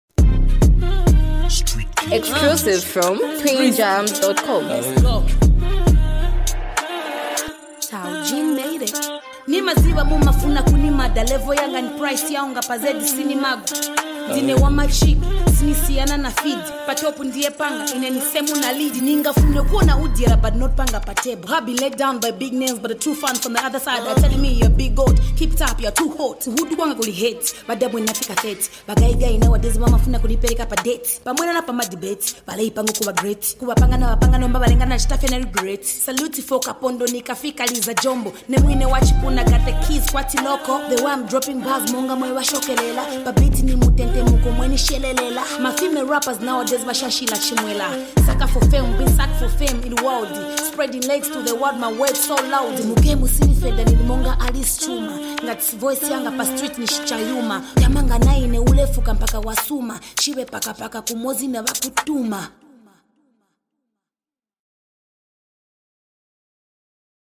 Music
From the jump, the freestyle is packed with attitude.
What stands out most is his confidence.